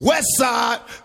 Tags: hip hop